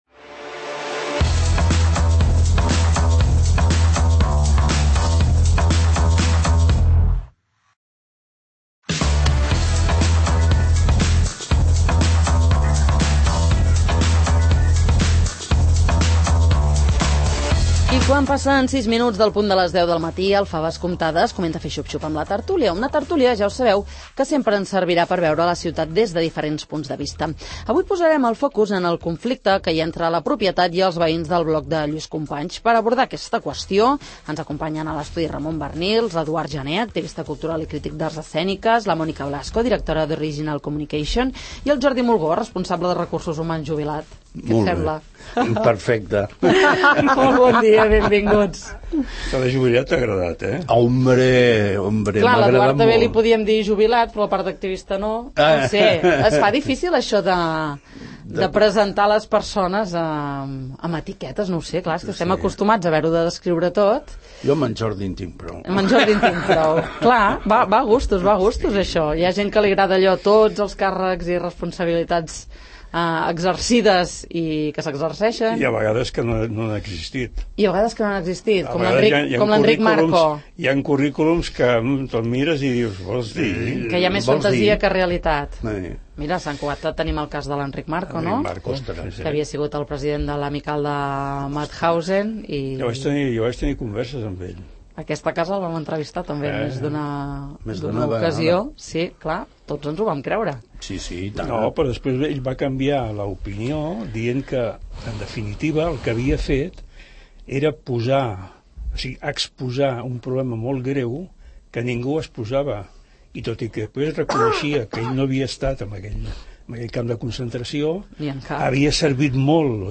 Per on passa la soluci� del conflicte del bloc de Llu�s Companys? En parlem a la tert�lia del 'Faves comptades'